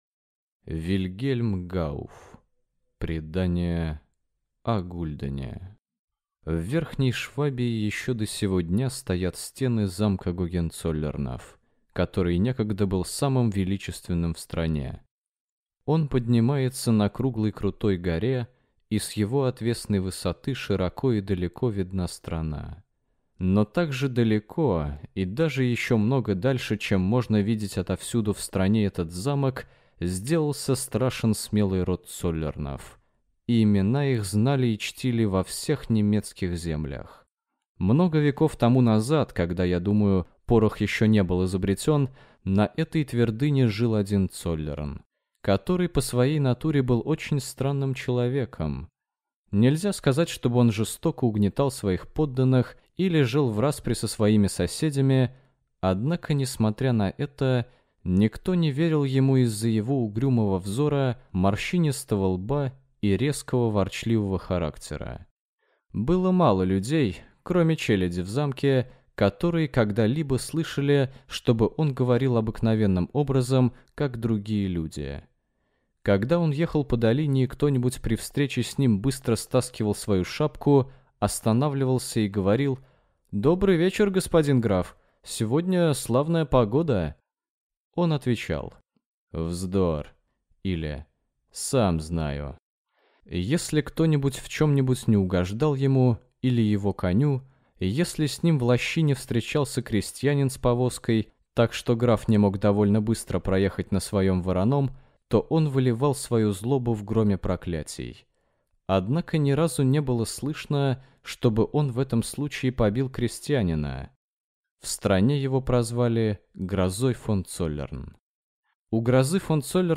Аудиокнига Предание о гульдене | Библиотека аудиокниг